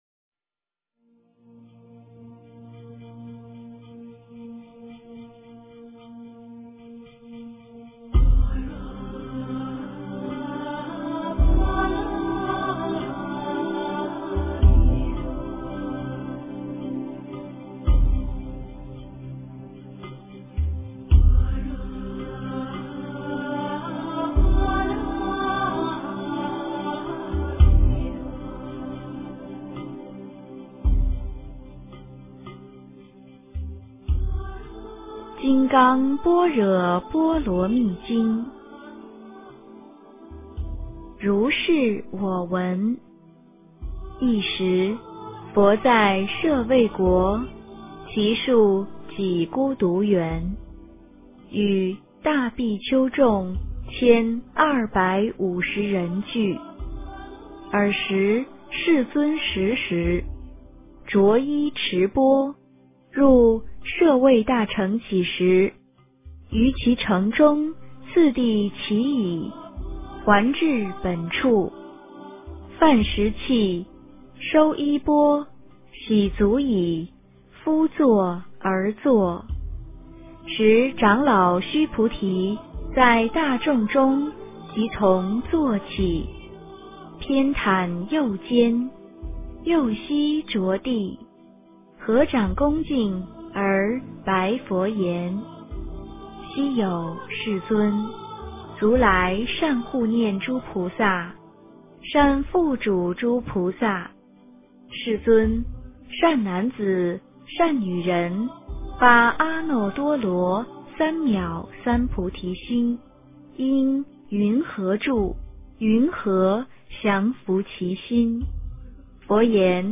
金刚经（念诵）
诵经